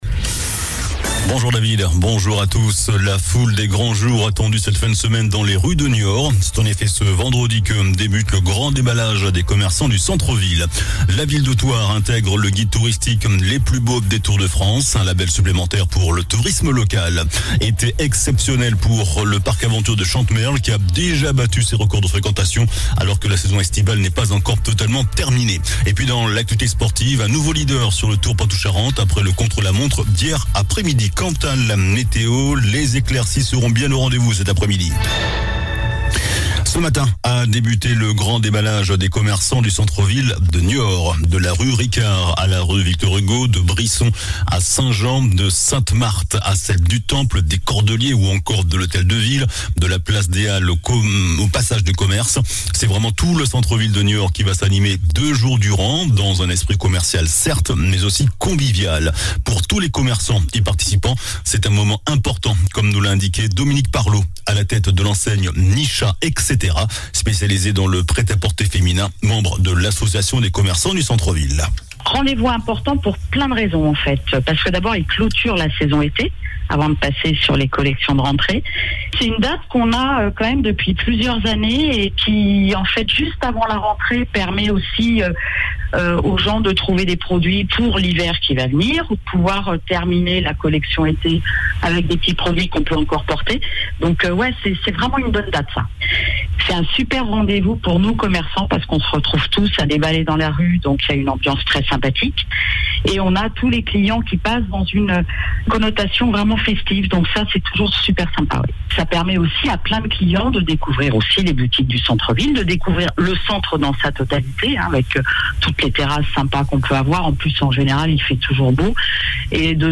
JOURNAL DU VENDREDI 26 AOÛT